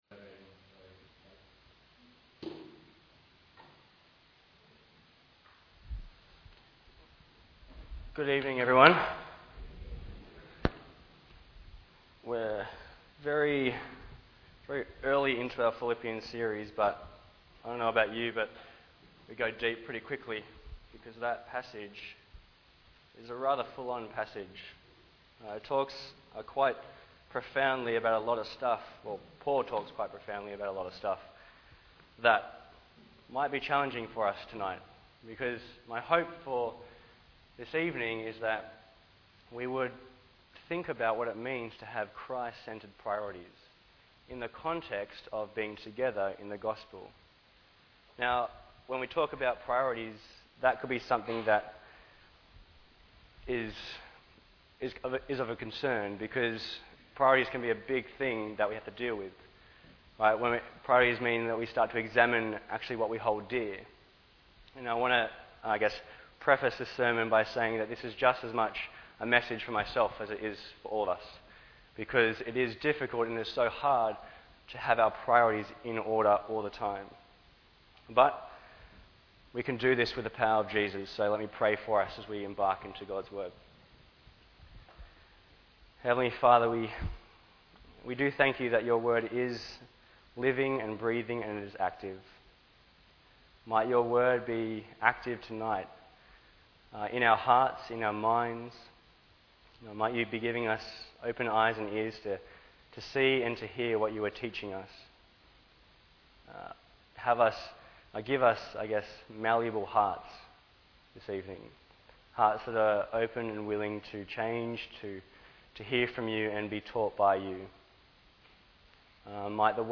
Bible Text: Philippians 1:12-26 | Preacher